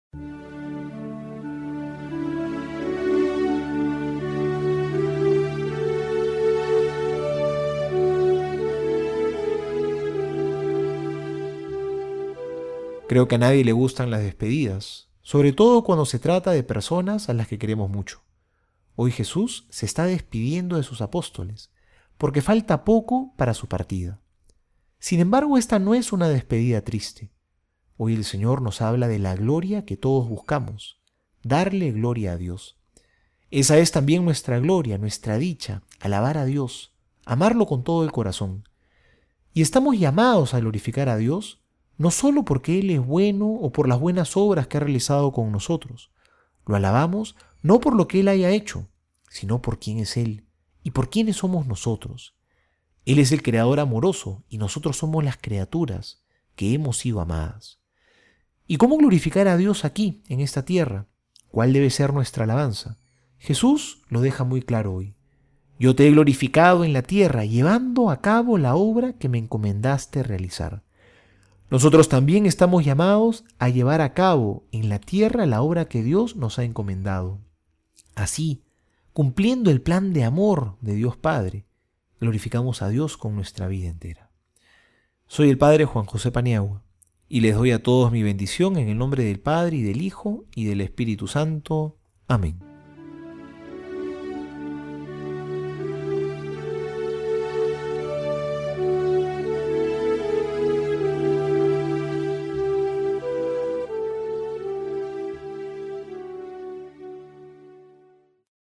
Homilía para hoy:
Martes homilia.mp3